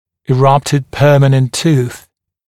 [ɪ’rʌptɪd ‘pɜːmənənt tuːθ] [и’раптид ‘пё:мэнэнт ту:с] прорезавшийся постоянный зуб